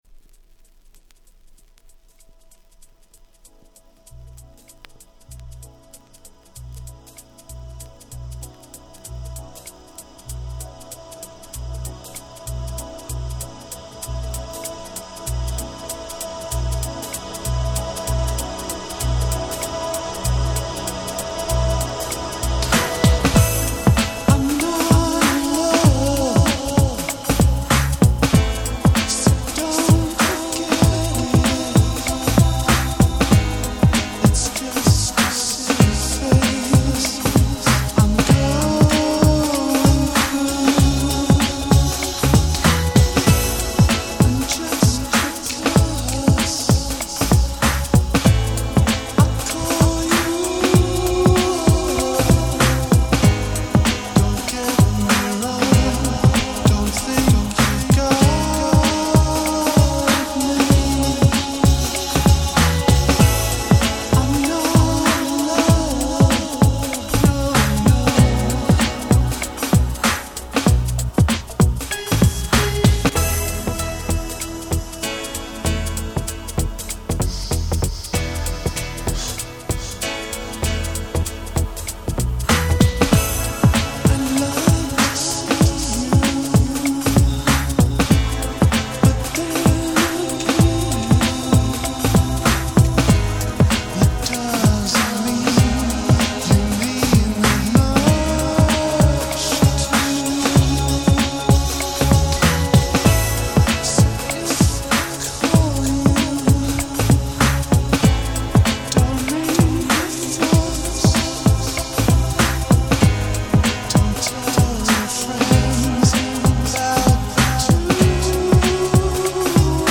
【Media】Vinyl 12'' Single
90' Nice Ground Beat !!